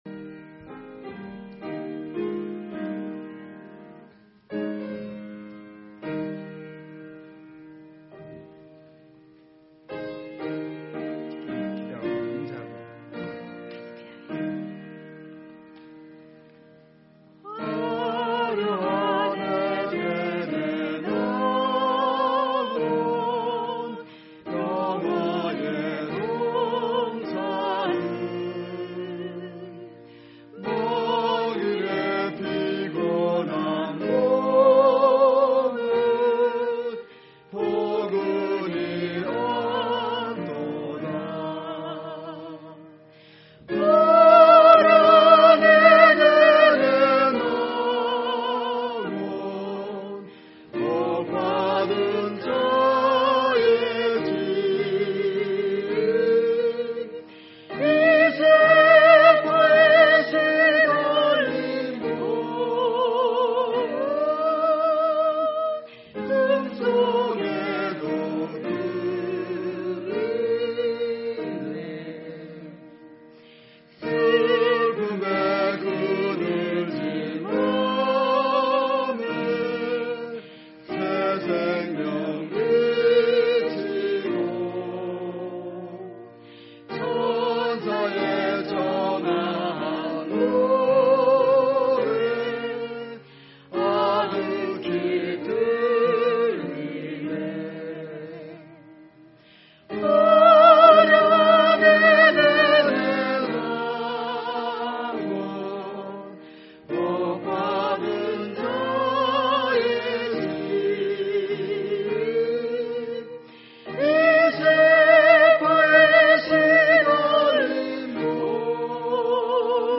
Special Music